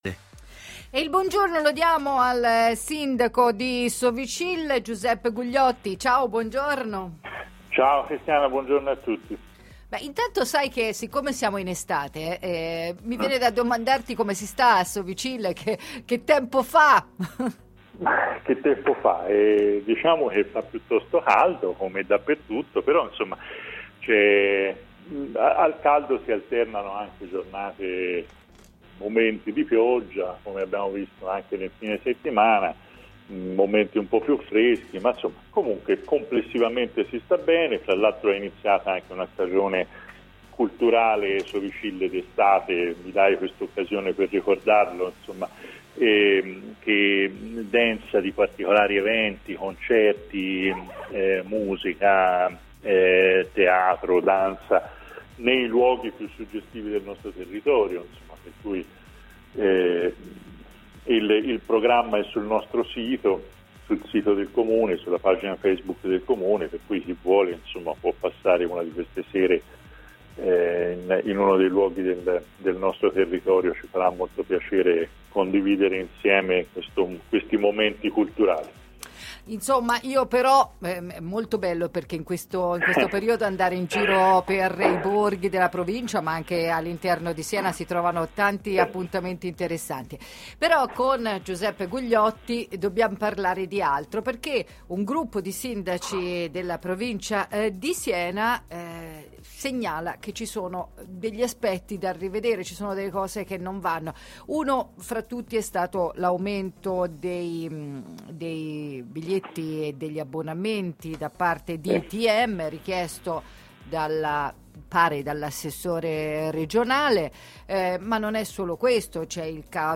Riprendere il controllo di Sei Toscana e delle altre aziende dei servizi pubblici, è quanto intendono fare i sindaci della provincia. Ne abbiamo parlato stamani, nella trasmissione Goodmorning time, con Giuseppe Gugliotti, sindaco di Sovicille che insieme ad altri colleghi sta lavorando per ripubblicizzare le aziende senesi.